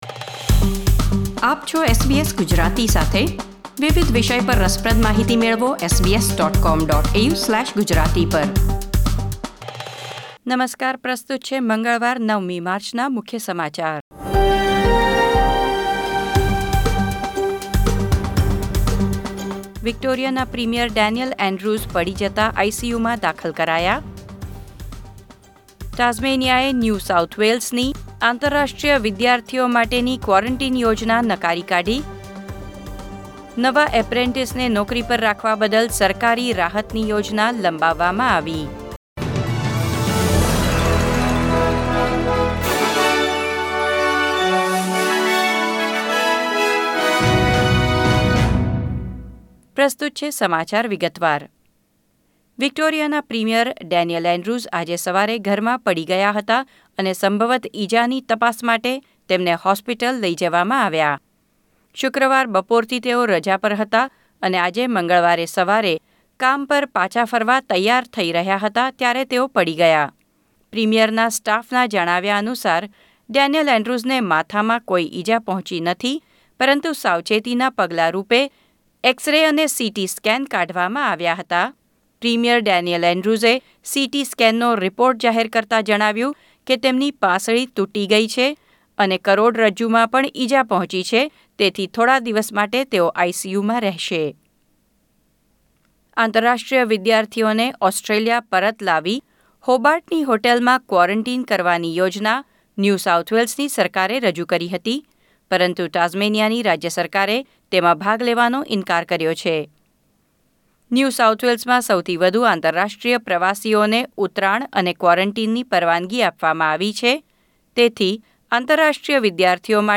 SBS Gujarati News Bulletin 9 March 2021